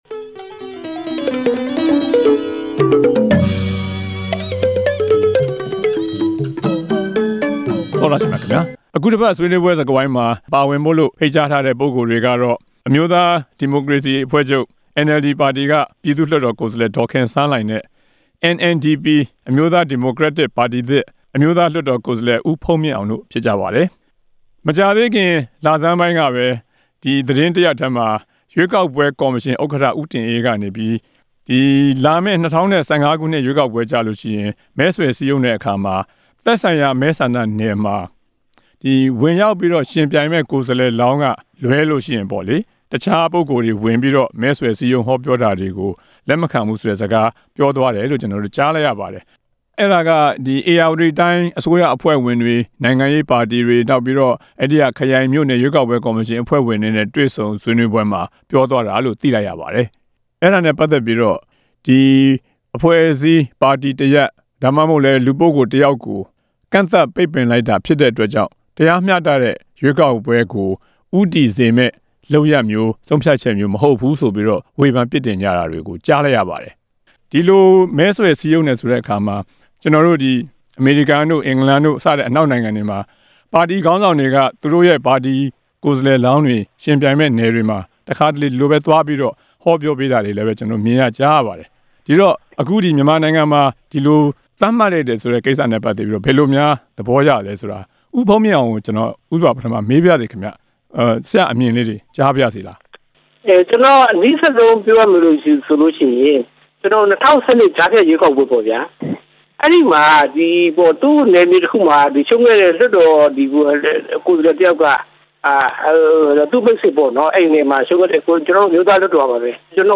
၂ဝ၁၅ ခုနှစ်ရွေးကောက်ပွဲအတွက် မဲဆွယ်စည်းရုံး ကြတဲ့အခါ သက်ဆိုင်ရာမဲဆန္ဒနယ်မှာ ဝင်ရောက်အရွေးချယ်ခံတဲ့ ကိုယ်စားလှယ်လောင်းတွေကိုသာ ဟောပြောခွင့်ပြုမယ်လို့ ရွေးကောက်ပွဲကော်မရှင် ဥက္ကဋ္ဌ ဦးတင်အေးက ပြောကြားခဲ့တဲ့ သတင်းနဲ့ ဆက်စပ်ပြီး NLD ပါတီပြည်သူ့လွှတ်တော် ကိုယ်စားလှယ် ဒေါ်ခင်စန်းလှိုင်နဲ့ NNDP အမျိုးသား ဒီမိုကရေစီပါတီသစ် အမျိုးသားလွှတ်တော် ကိုယ်စားလှယ် ဦးဘုန်းမြင့်အောင် တို့နဲ့ ဆွေးနွေးထားပါတယ်။